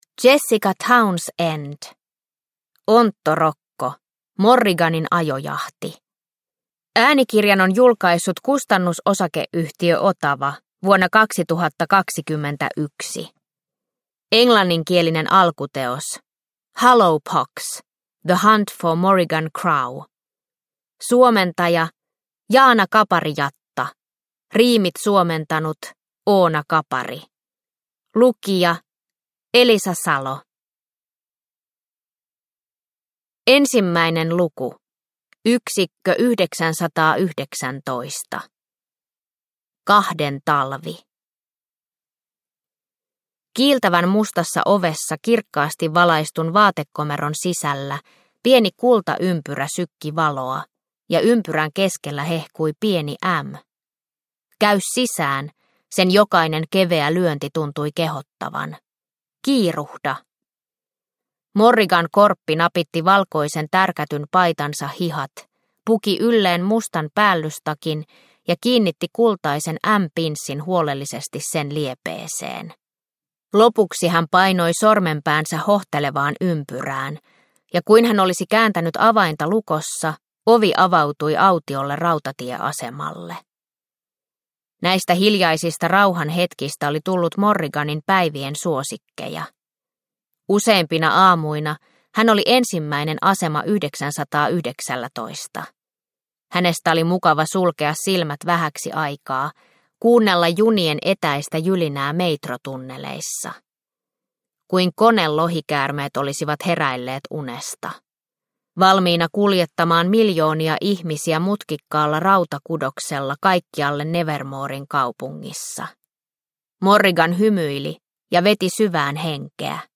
Onttorokko - Morriganin ajojahti – Ljudbok – Laddas ner